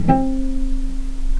vc_pz-C4-pp.AIF